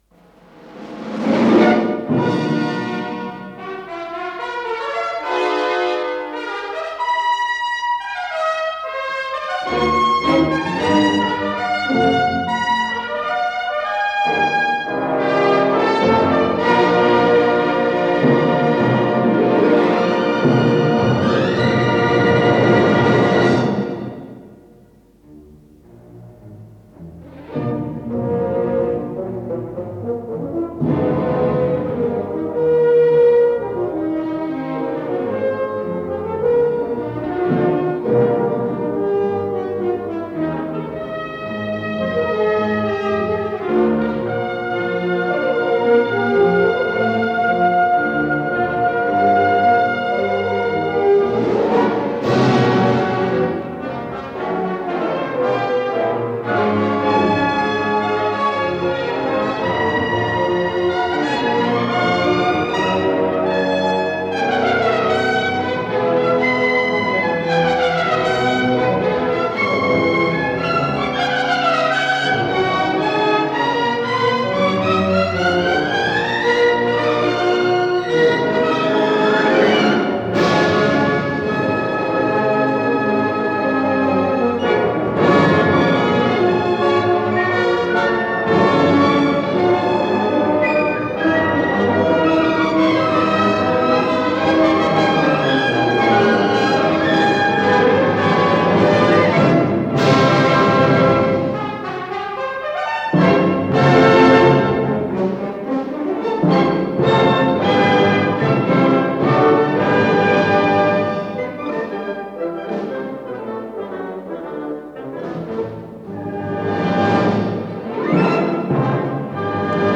П-05016 — Симфоническая поэма
Исполнитель: Большой симфонический оркестр Польского радио и телевидения